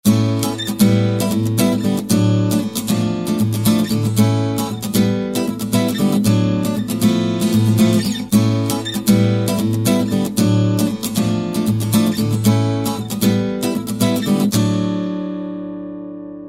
гитара
инструментальные